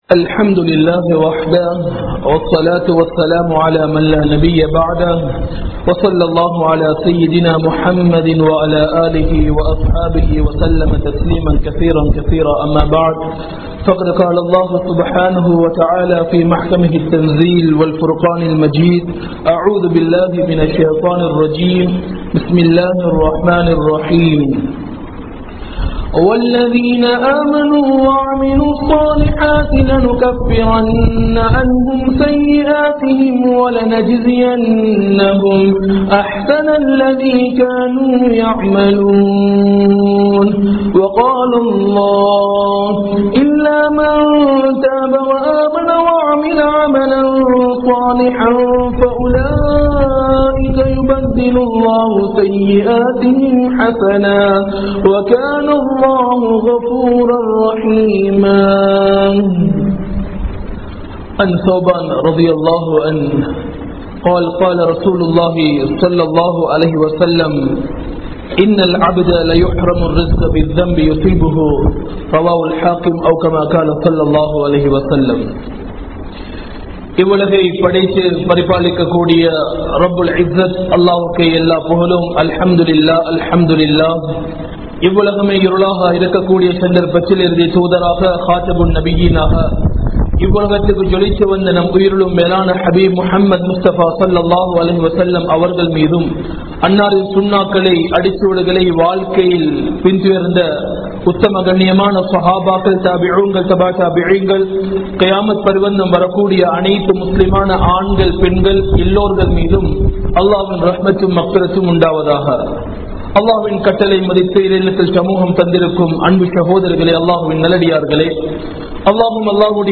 Siriya Paavangalai Alikkum Seyalhal (சிறிய பாவங்களை அழிக்கும் செயல்கள்) | Audio Bayans | All Ceylon Muslim Youth Community | Addalaichenai
Galle, Kanampittya Masjithun Noor Jumua Masjith